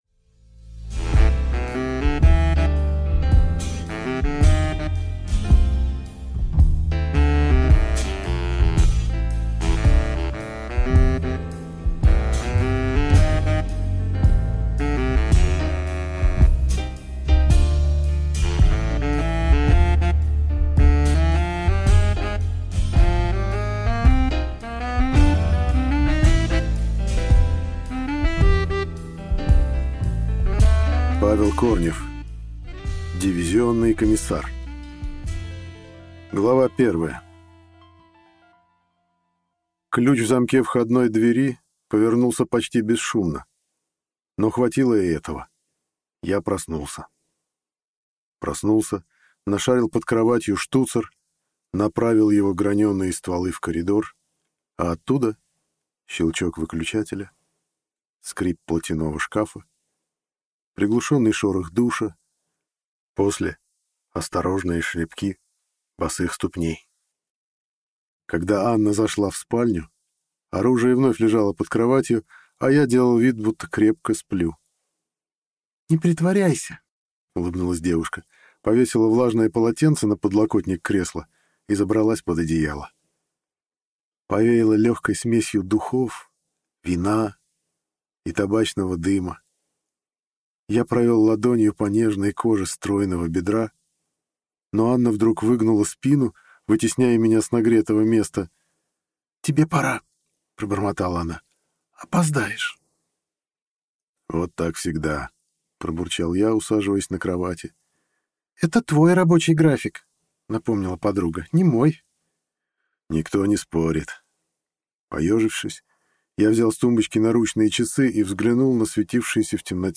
Аудиокнига Дивизионный комиссар | Библиотека аудиокниг